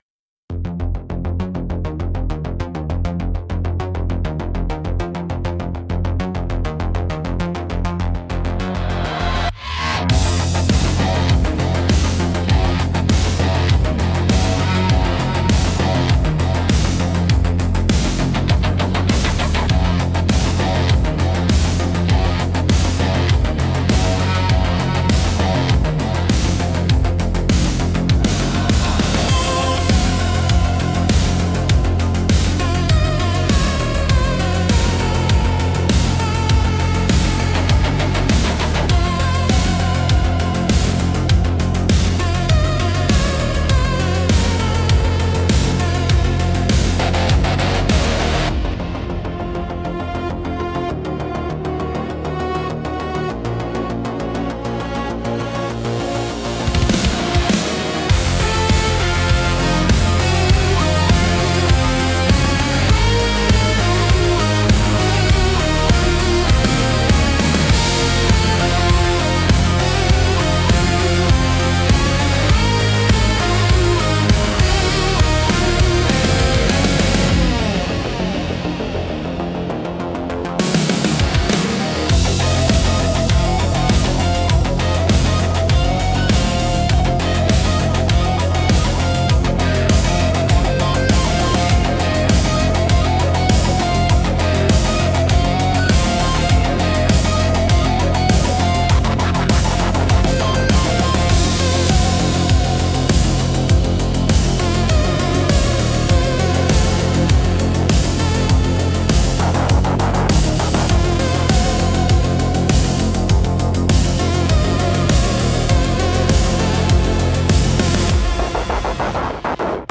Instrumental - A Fistful of Twang